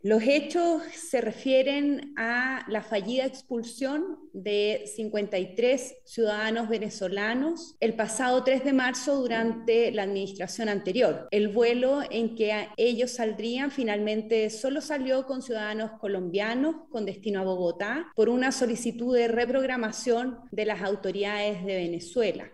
También se invitó al subsecretario del interior Manuel Monsalve -quien está en una visita a la zona norte del país- y al subsecretario de prevención del delito Eduardo Vergara, pero solo participó la secretaria de estado de manera telemática.
Posterior a ello se dio paso a que la ministra -en un tono mucho más calmado y pausado que como se acostumbra a verla tomara la palabra.